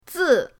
zi4.mp3